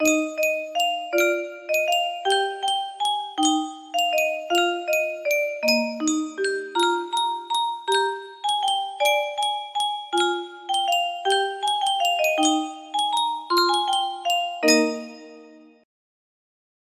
Yunsheng Music Box - God Save the Queen Y917 music box melody
Full range 60